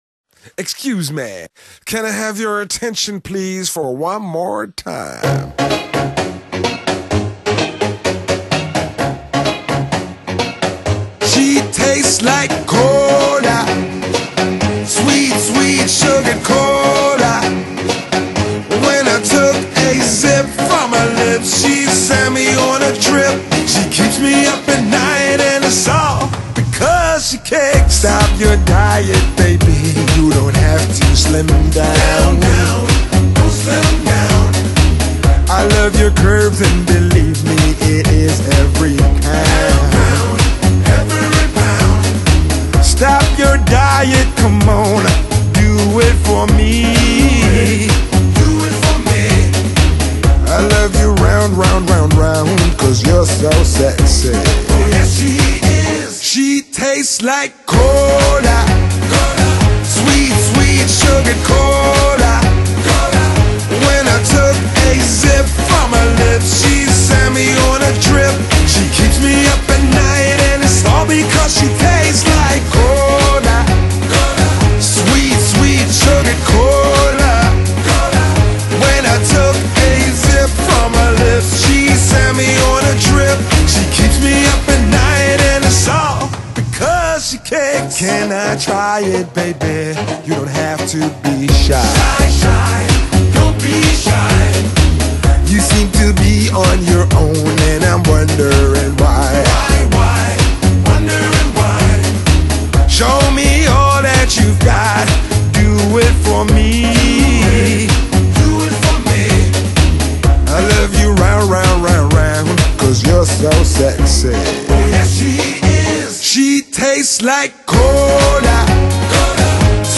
【拉丁曼波男聲】